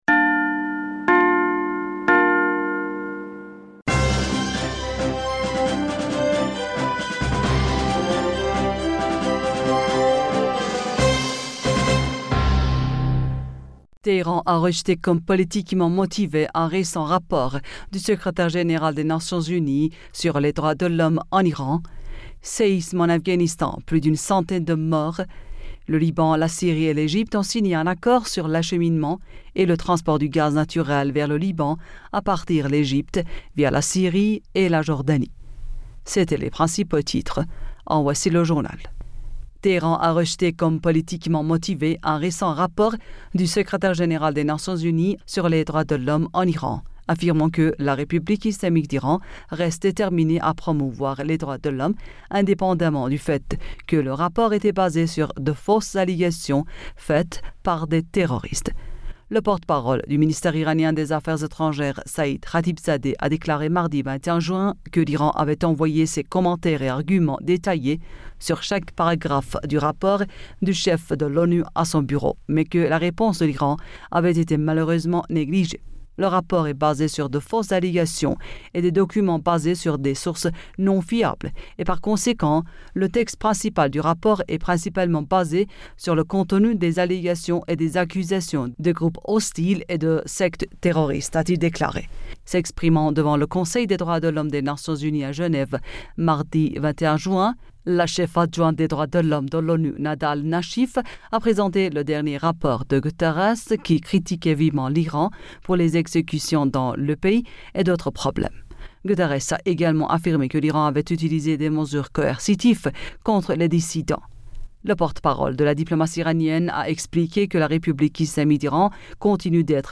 Bulletin d'information Du 22 Juin